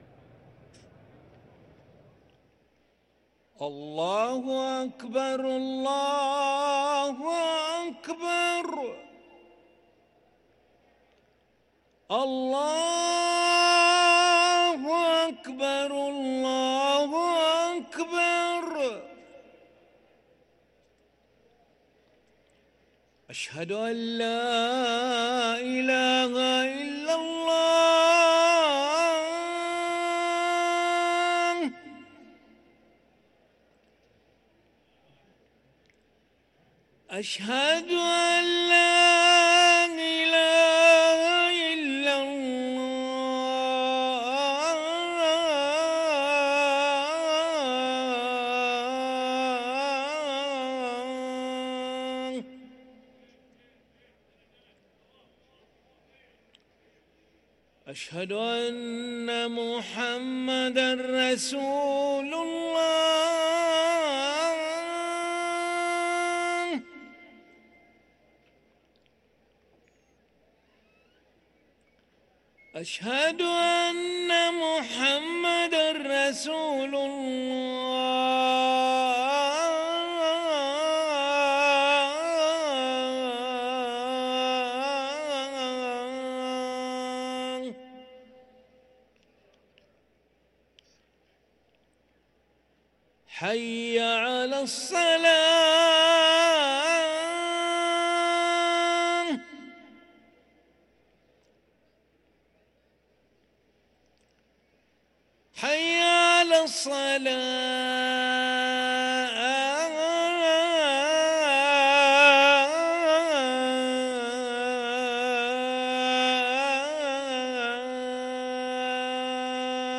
أذان العشاء للمؤذن علي ملا الخميس 24 شعبان 1444هـ > ١٤٤٤ 🕋 > ركن الأذان 🕋 > المزيد - تلاوات الحرمين